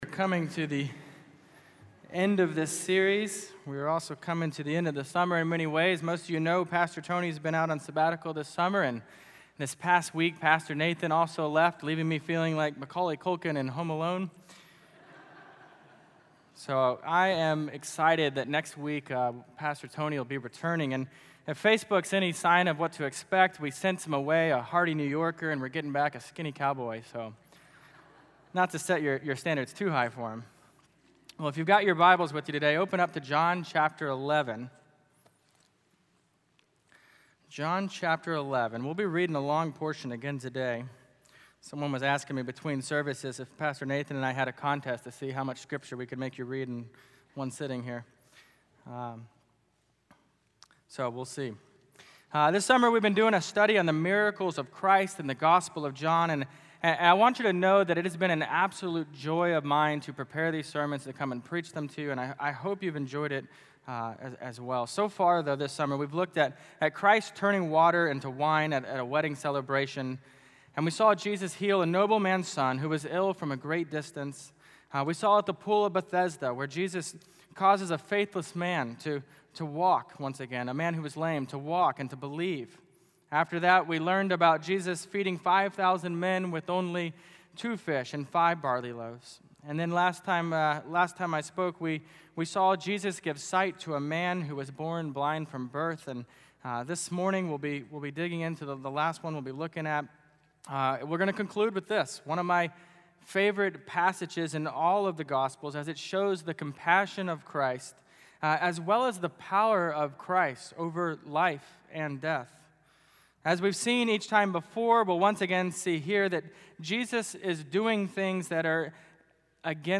Miracles of Jesus in the Gospel of John Passage: John 11:1-45 Service Type: Morning Worship I. The Purpose